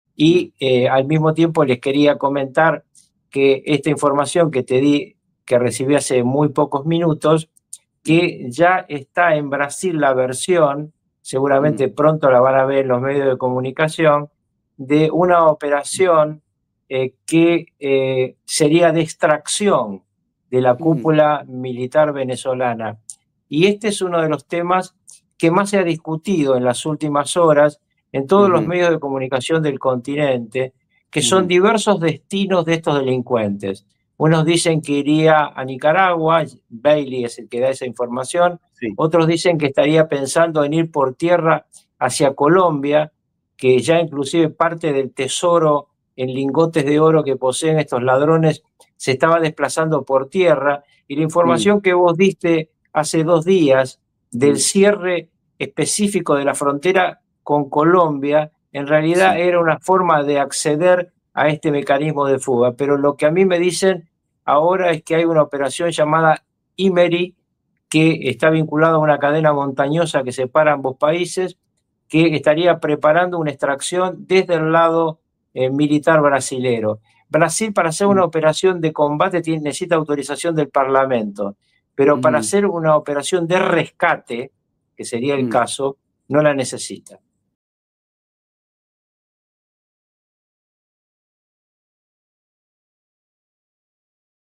El exvicepresidente Carlos Ruckauf habló sobre los posibles destinos de fuga del narcoterrorista Nicolás Maduro